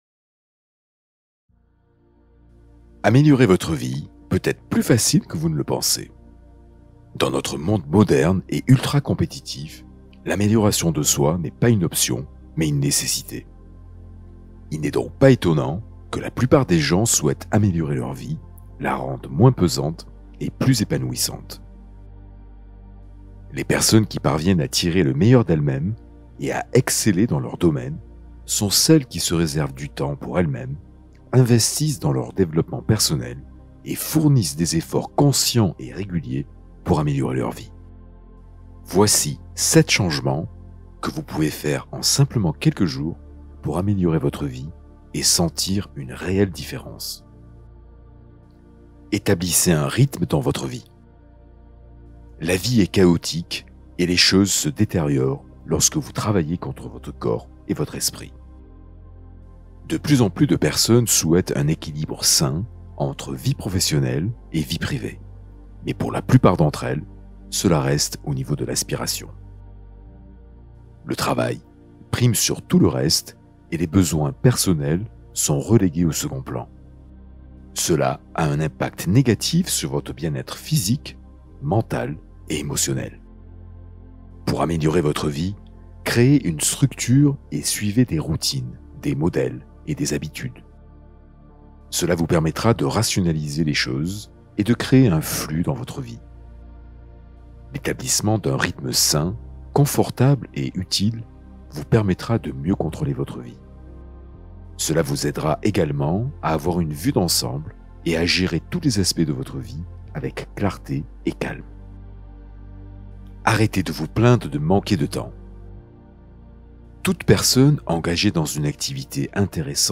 432 Hz : guidance neurologique pour détente et cohérence